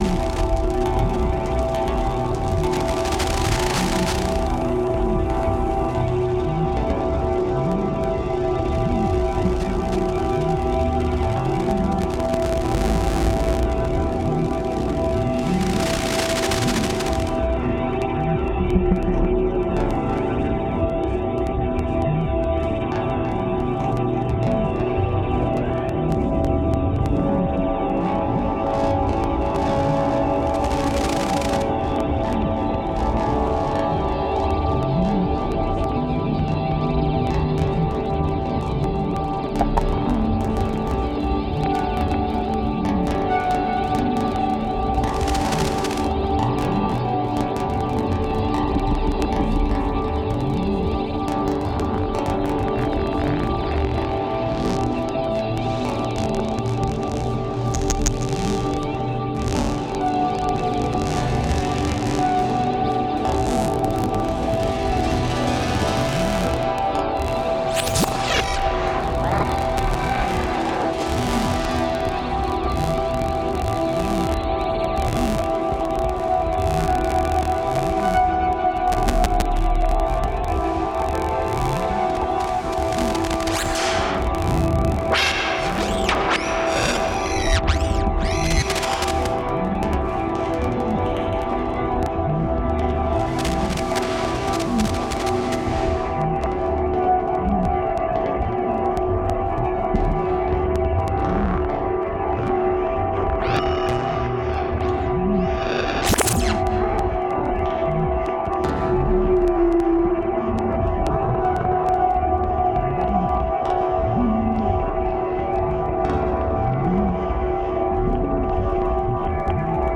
The 10-channel audiovisual installation, set on sound ecology, is based on virtual film set elements recorded in a natural habitat. At the same time, the objects act as sound triggers of the multi-channel audiovisual composition. Digitally processed landscape elements resonate in a network depicting the relationships between seemingly separate fragments of the natural environment.